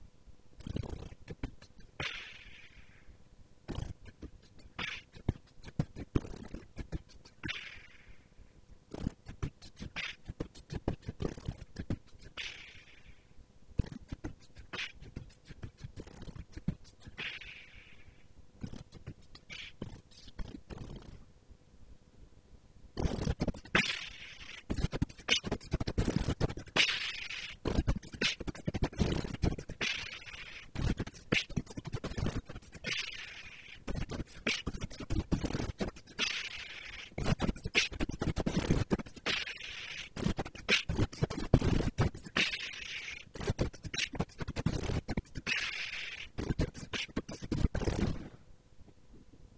Медленный бит